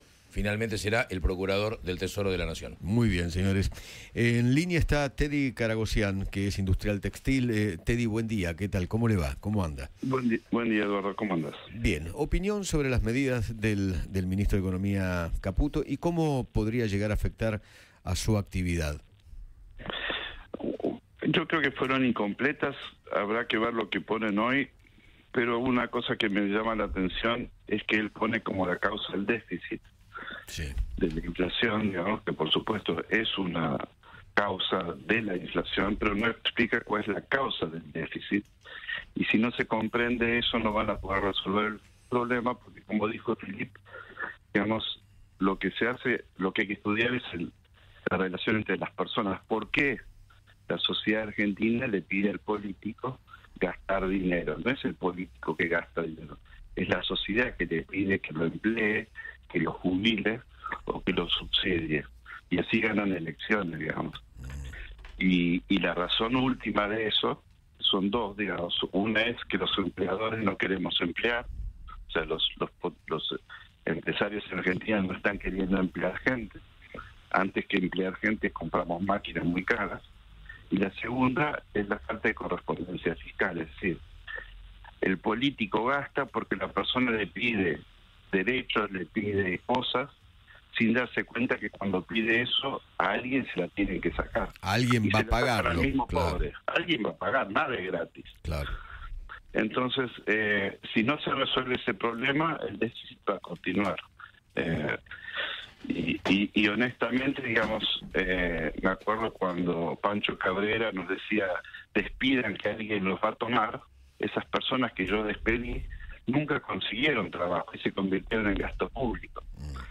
Teddy Karagosyán, empresario textil, conversó con Eduardo Feinmann sobre las medidas del ministro de Economía, Luis Caputo, y opinó cómo podría llegar a afectar a su actividad.